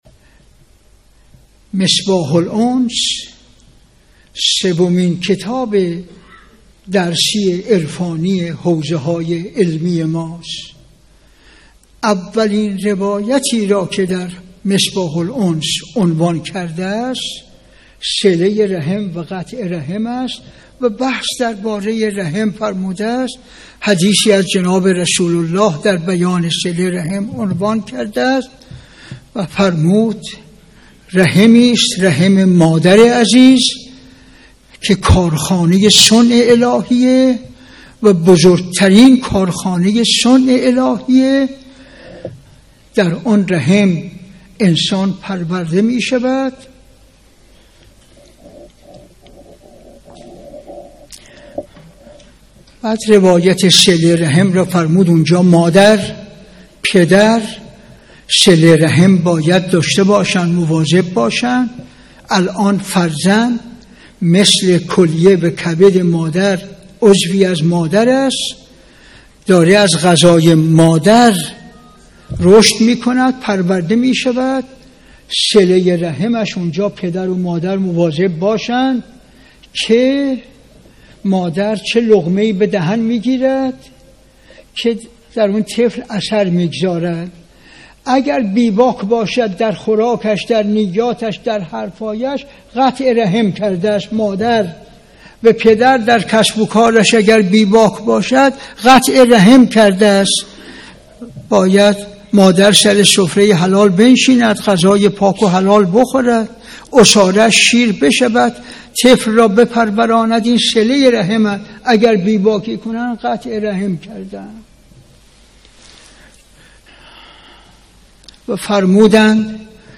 به گزارش پایگاه خبری 598، مرحوم علامه حسن زاده در یکی از سخنرانی‌های خود به موضوع «رحم مادر، کارگاه سنّت الهی و بزرگ‌ترین عرصهٔ تجلی شأن الهی» اشاره کردند که تقدیم شما فرهیختگان می شود.